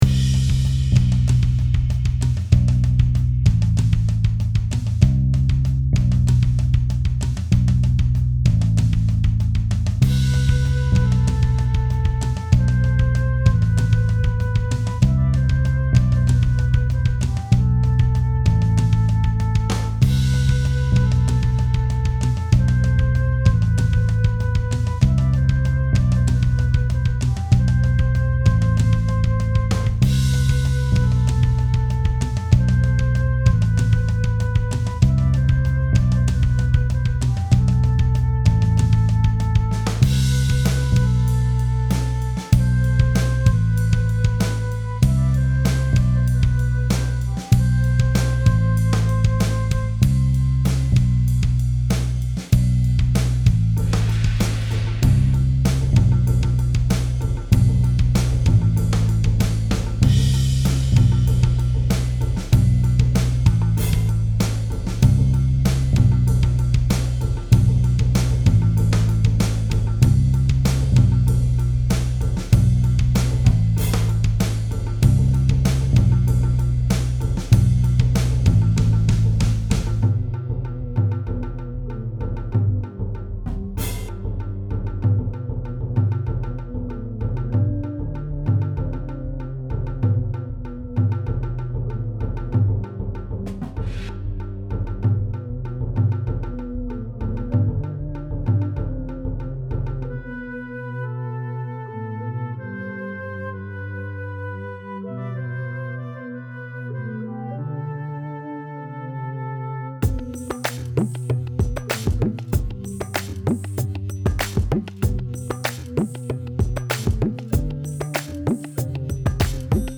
Tribalish